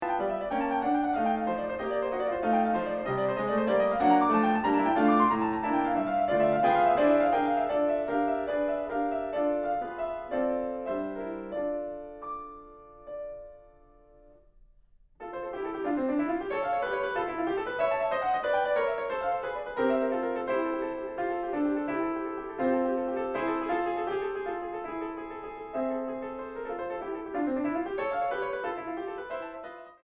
En sol mayor. Tempo giusto 1.45